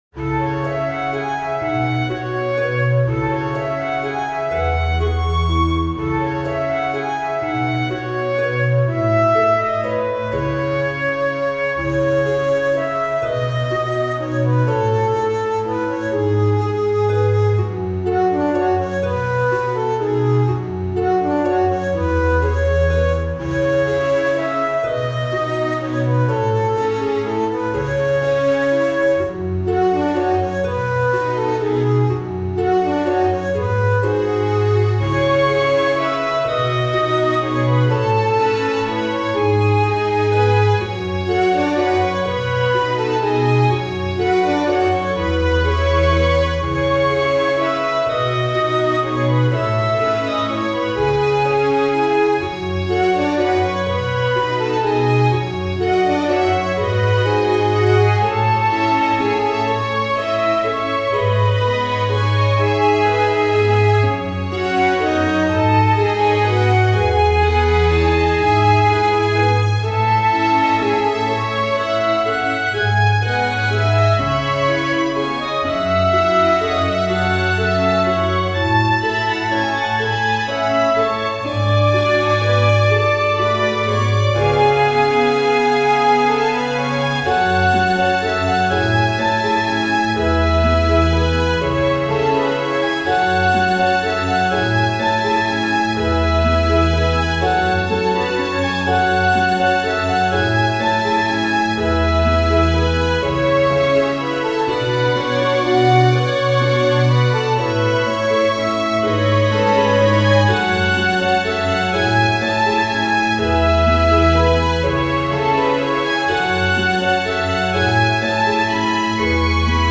フリーBGM イベントシーン 幻想的・神秘的
フェードアウト版のmp3を、こちらのページにて無料で配布しています。